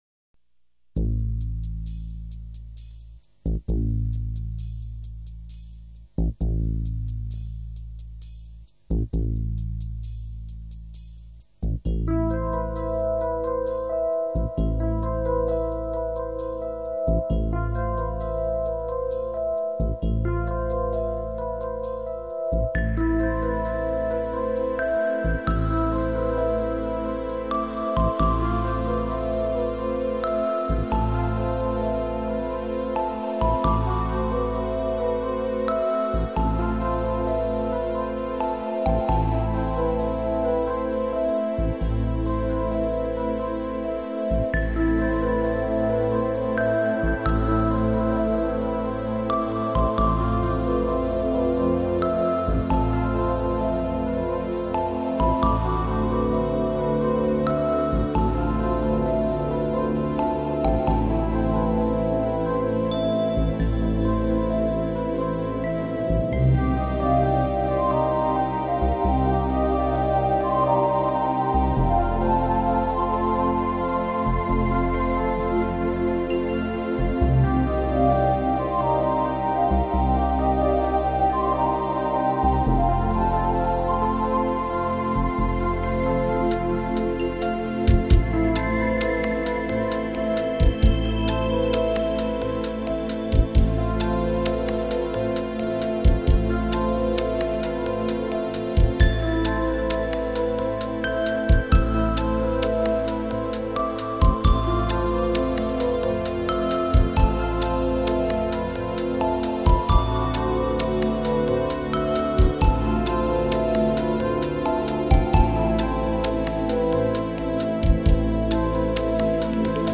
一首如河流般幽静，如大海般浩淼的音乐在每每静夜被反复聆听，直到糜烂在心里。